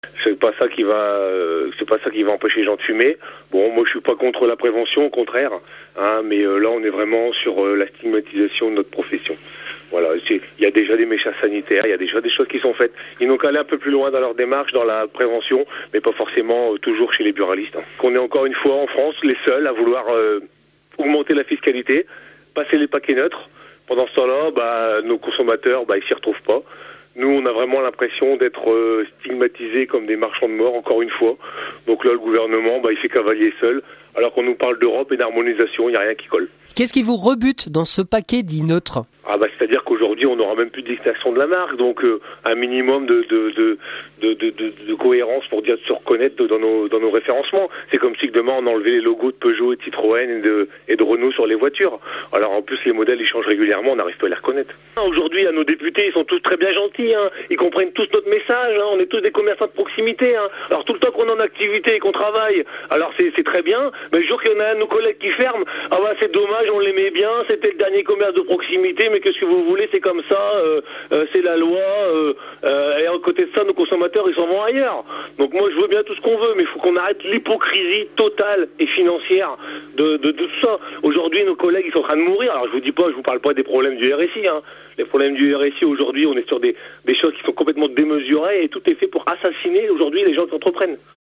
ce mardi après midi, symboliquement des centaines de buralistes ont baissé leur rideau. interview